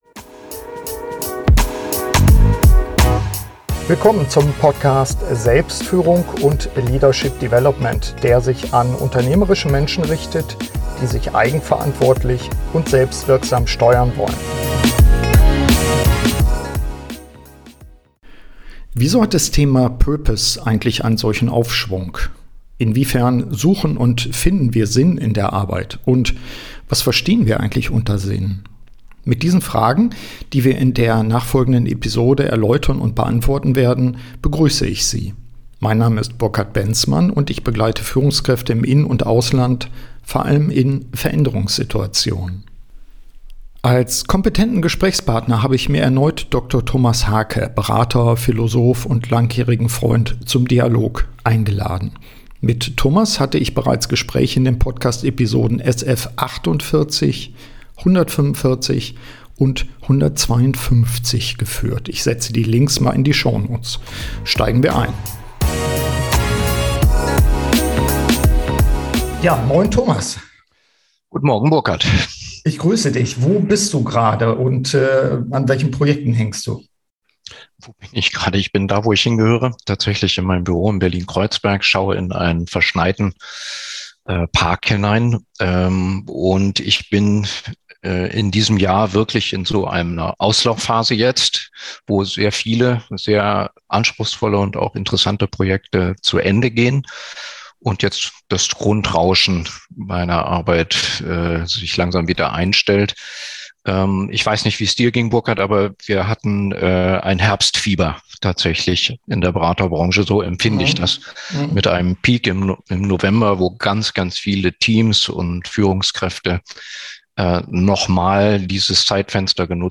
SF164 Arbeit mit Sinn - Update-Gespräch